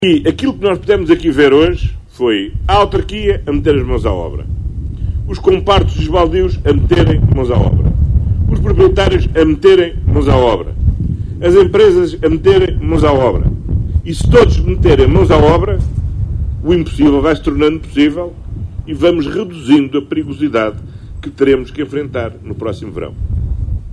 Mãos à obra no combate aos incêndios com o envolvimento de todos foi o compromisso deixado pelo primeiro ministro em Riba de Âncora.